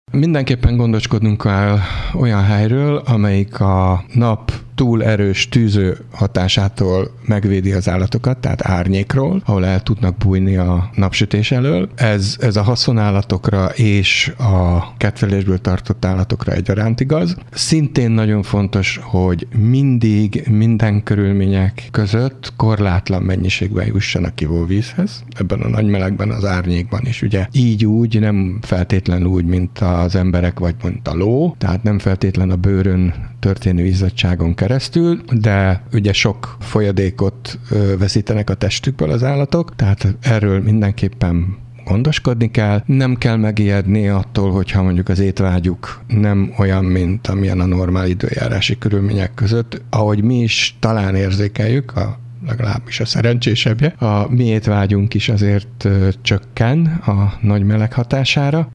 Hírek